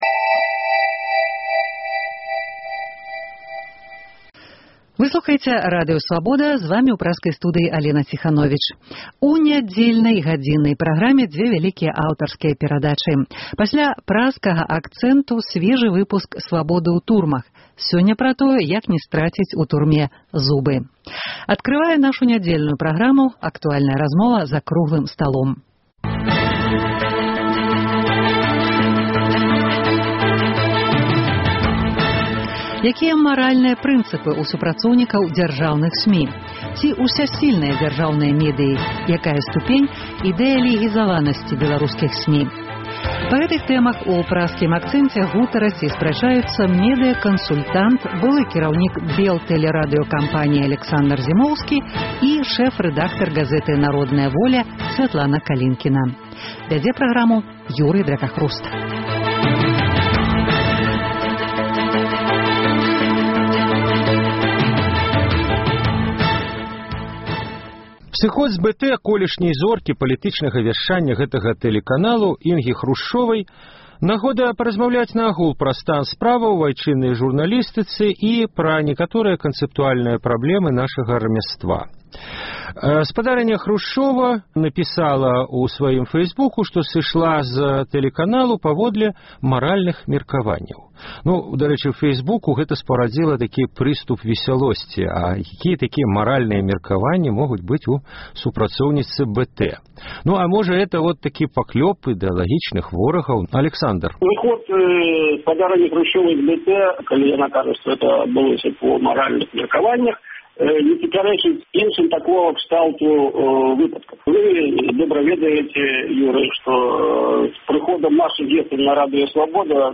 Дыскусія